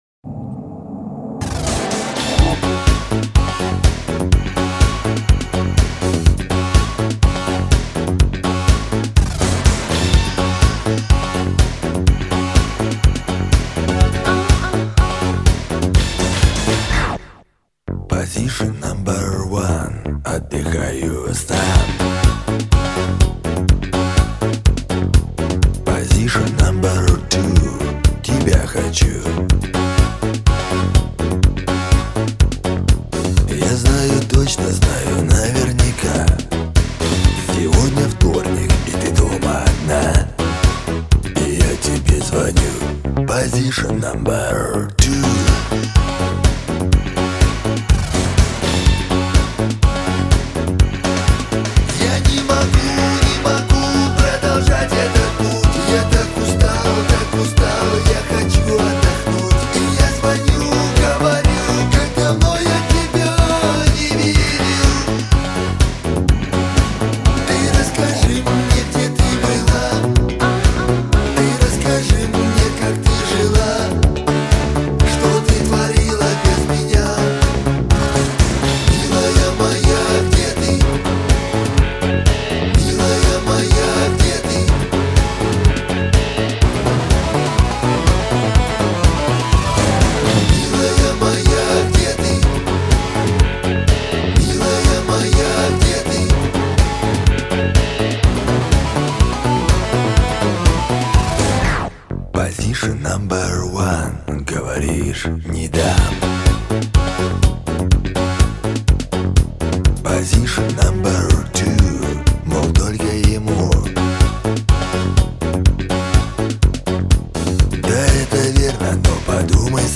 в жанре поп-музыки с элементами романтической лирики